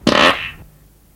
文件夹里的屁 " 屁08
描述：从freesound上下载CC0，切片，重采样到44khZ，16位，单声道，文件中没有大块信息。准备使用！在1个文件夹中有47个屁;）
Tag: 喜剧 放屁 效果 SFX soundfx 声音